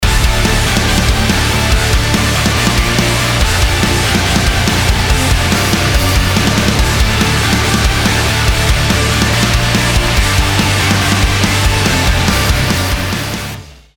• Качество: 320, Stereo
громкие
без слов
Alternative Metal
бодрые
christian rock